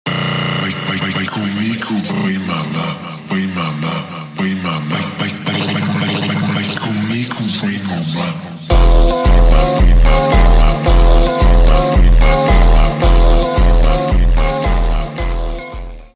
Best Piggy Jumpscare in 2020 sound effects free download